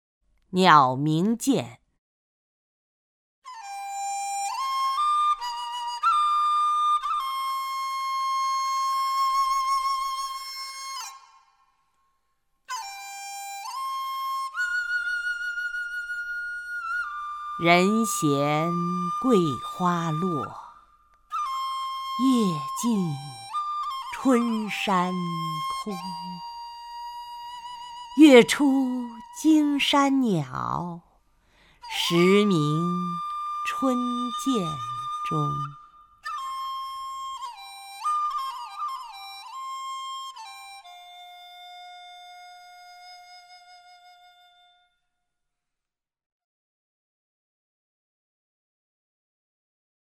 曹雷朗诵：《鸟鸣涧》(（唐）王维) （唐）王维 名家朗诵欣赏曹雷 语文PLUS